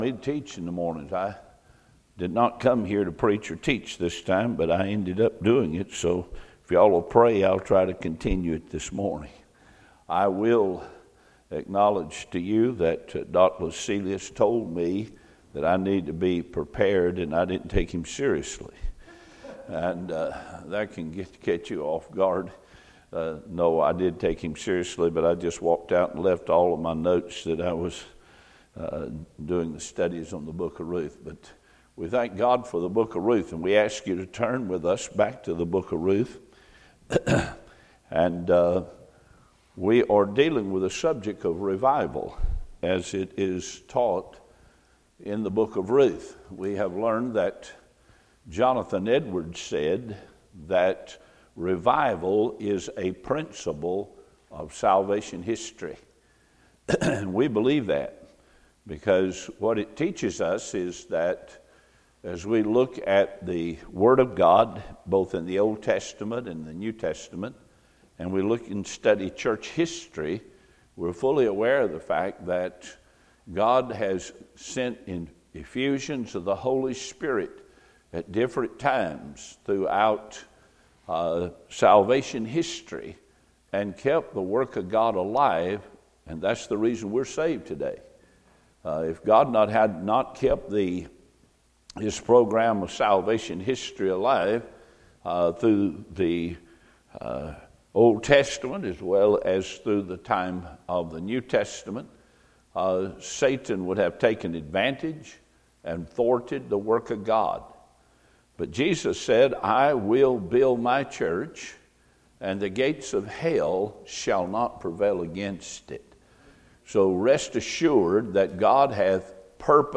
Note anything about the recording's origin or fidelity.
Series: 2017 August Conference